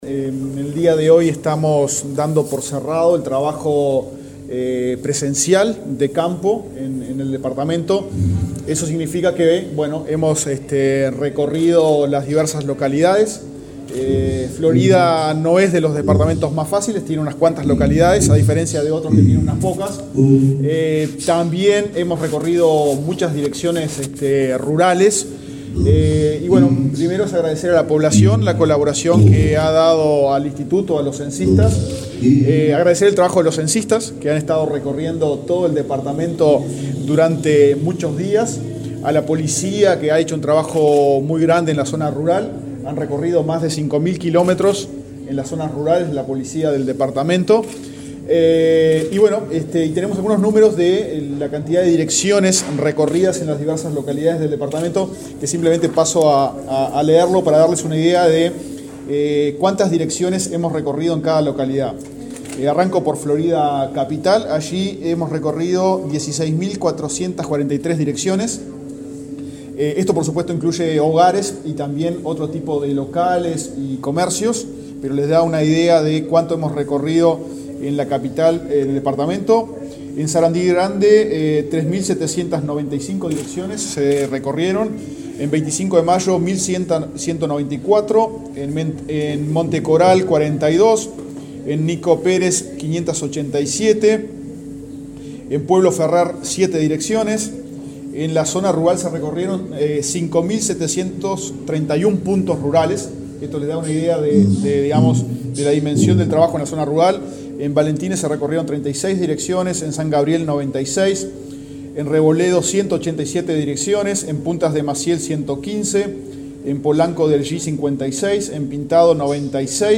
Declaraciones del director del INE, Diego Aboal
El director del Instituto Nacional de Estadística (INE), Diego Aboal, informó a la prensa, en Florida, sobre la finalización de la etapa presencial